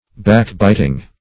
Backbiting \Back"bit`ing\, n.